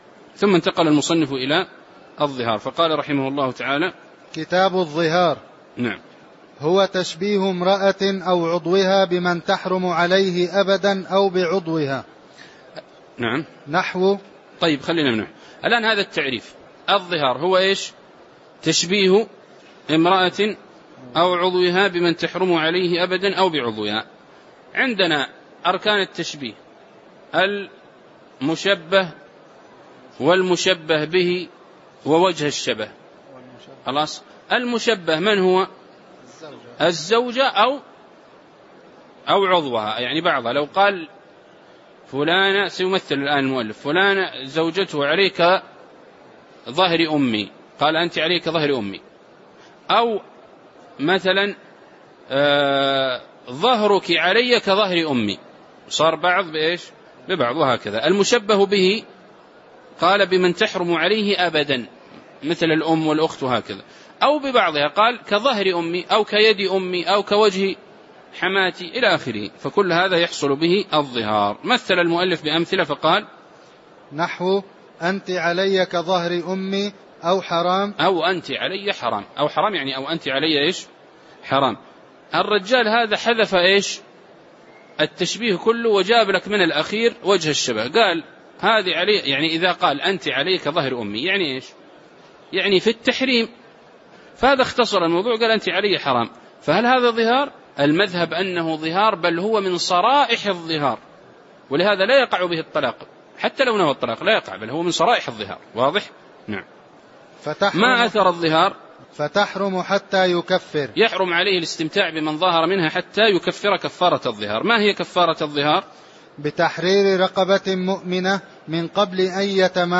تاريخ النشر ٢١ شوال ١٤٣٩ هـ المكان: المسجد النبوي الشيخ